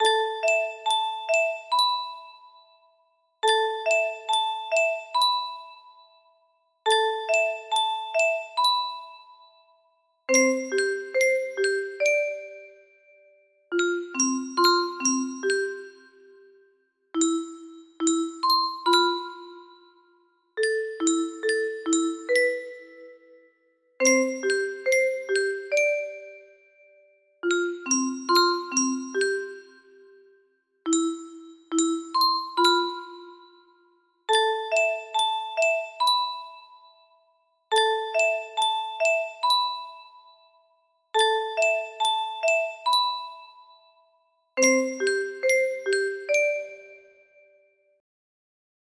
Clone of Sakura, Sakura (Japanese folk song) music box melody